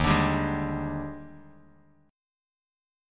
D#M11 chord